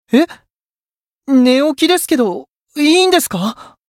觉醒语音 诶，我才刚起床……可以吗？